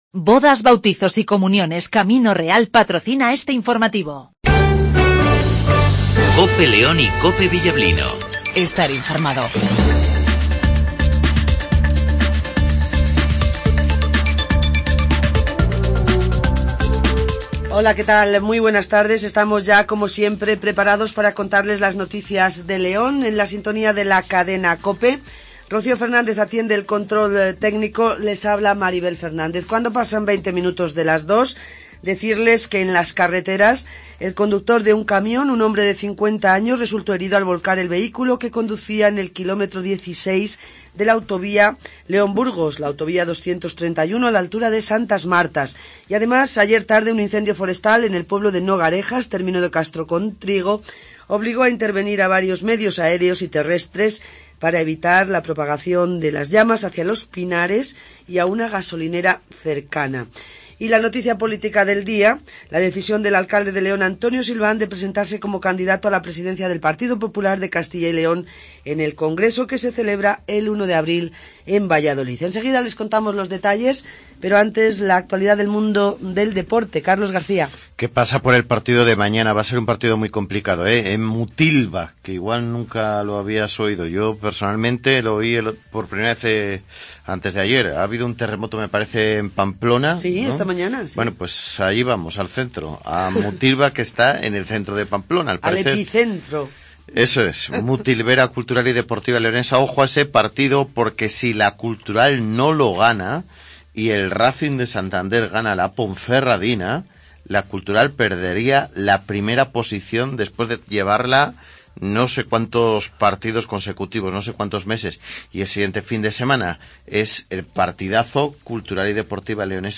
INFORMATIVO MEDIODIA